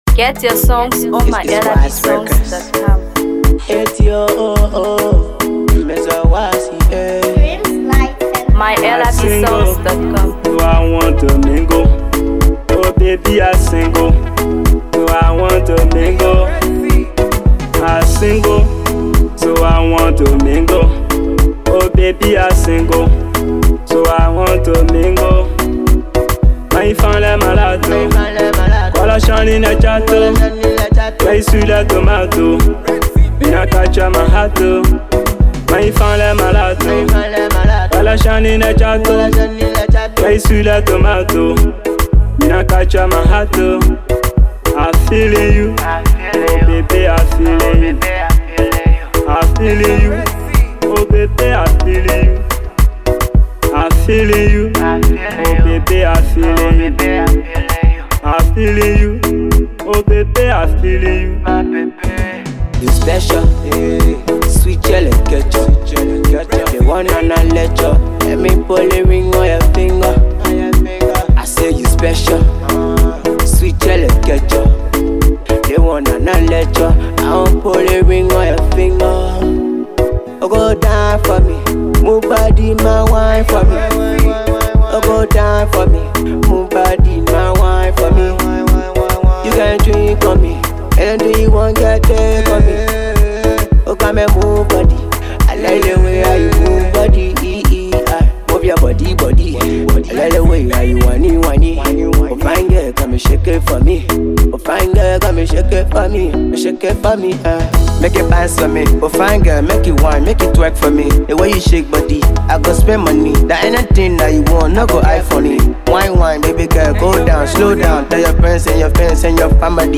Afro Pop
marked by its vibrant beats and catchy melodies